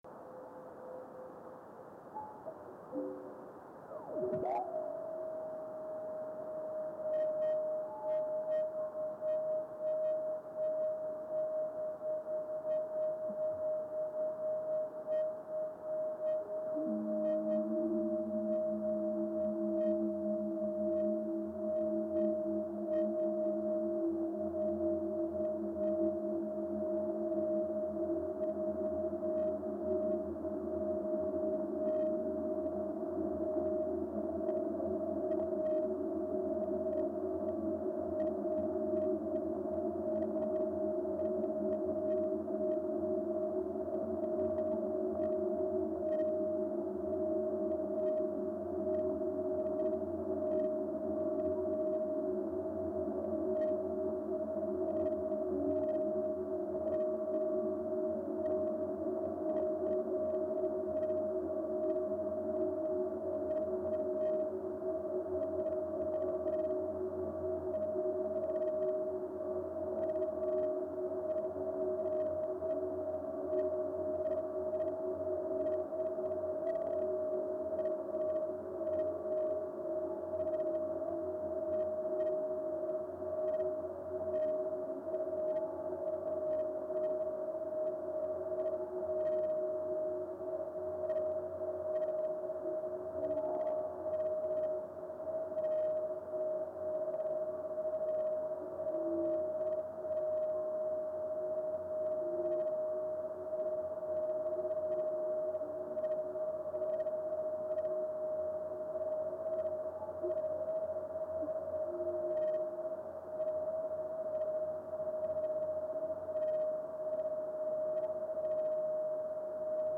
dual frequency forward scatter system;   video and stereo sound:
A second audible fireball happened 20 seconds after the first fireball which added another long reflection that merged sonically.